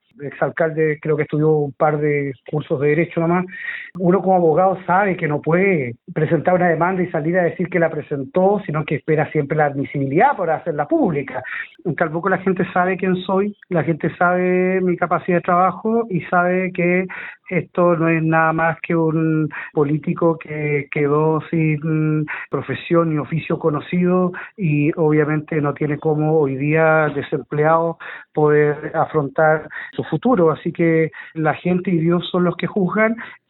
cuna-calbuco-alcalde.mp3